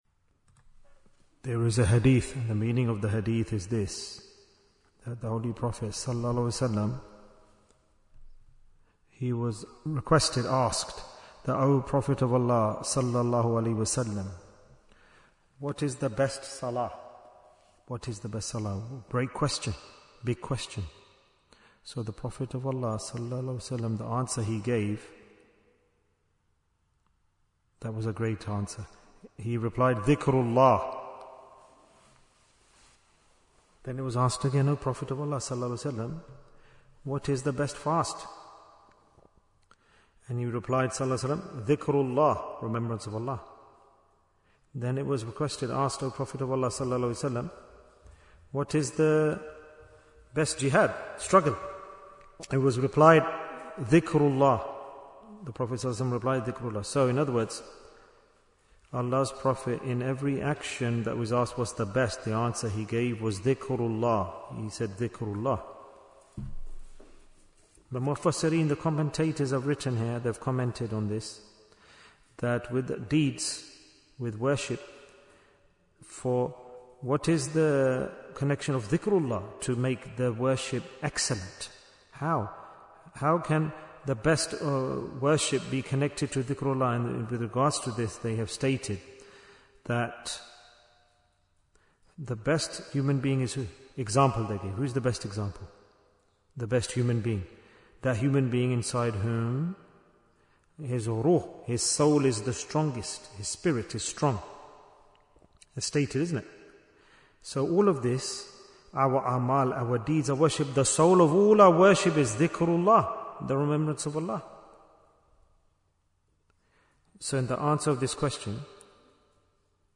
Jewels of Ramadhan 2026 - Episode 20 Bayan, 31 minutes28th February, 2026